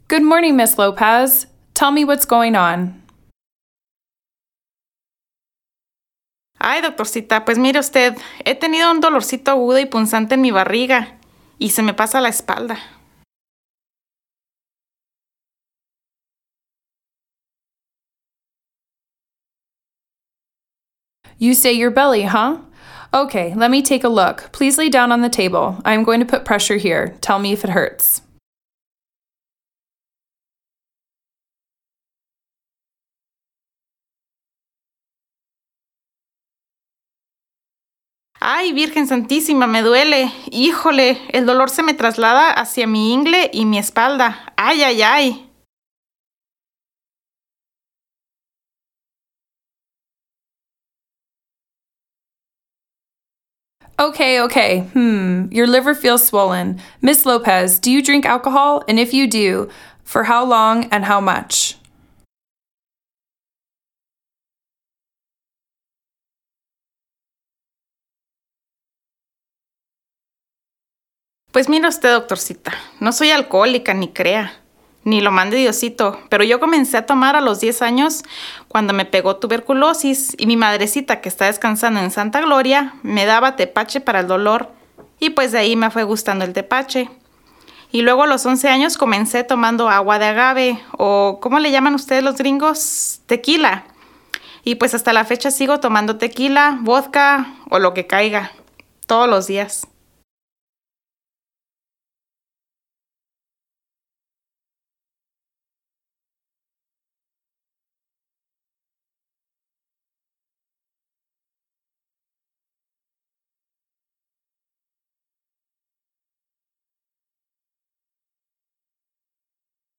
VCI-Practice-Dialogue-07-Abdominal-Pain-EN-SP.mp3